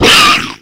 flesh_attack_0.ogg